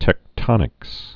(tĕk-tŏnĭks)